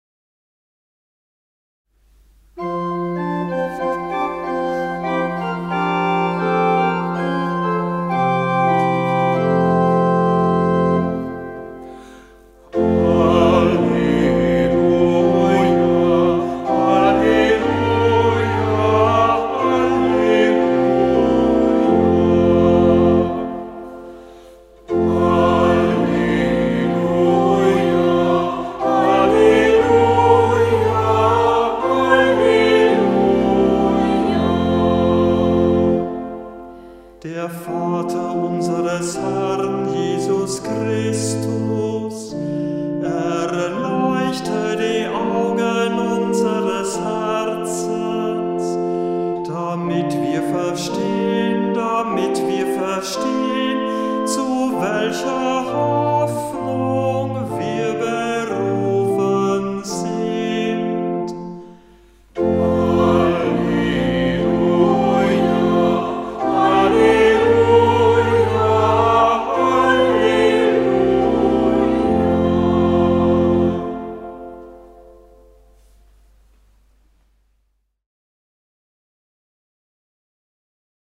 Halleluja aus dem Gotteslob